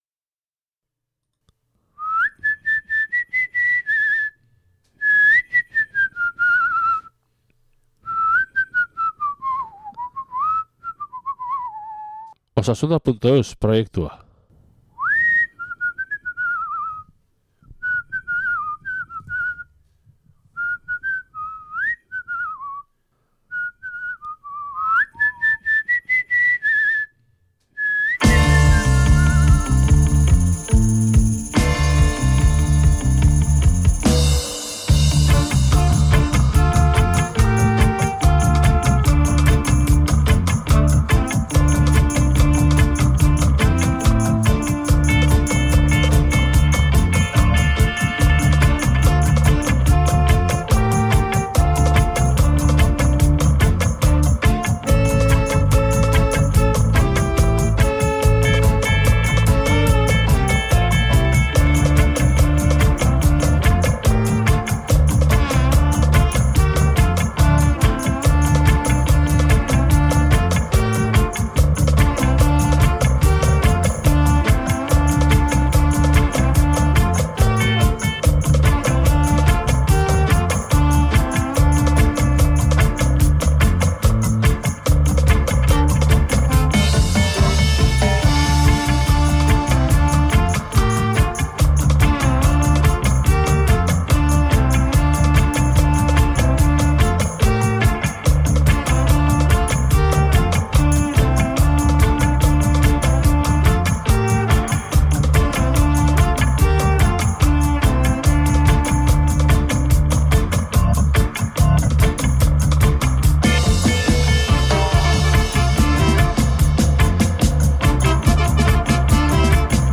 reggae, reggae-ska, dub